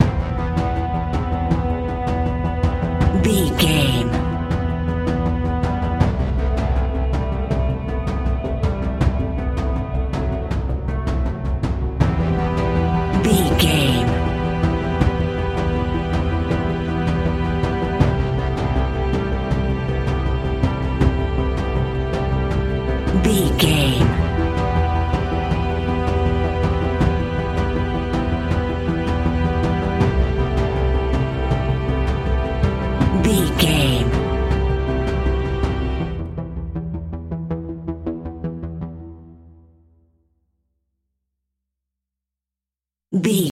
In-crescendo
Aeolian/Minor
ominous
dark
eerie
synthesizer
percussion
horror music